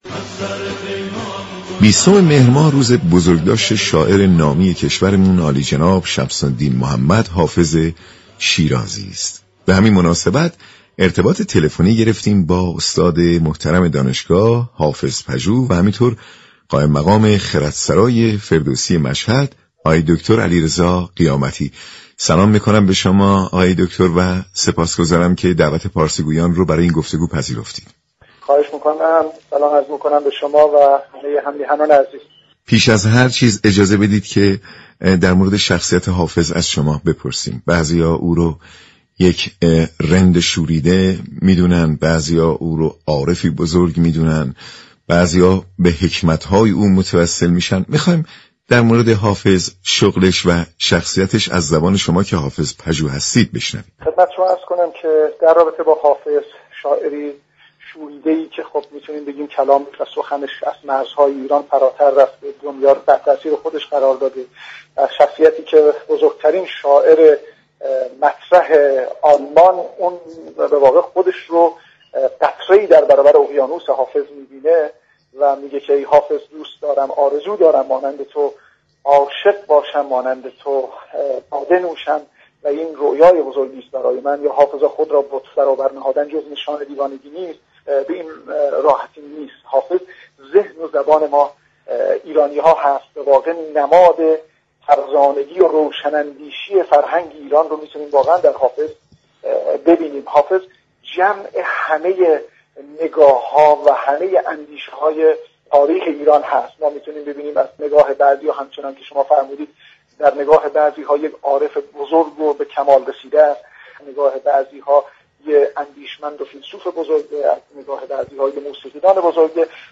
درباره حافظ و شخصیت او گفت و گو كرده است.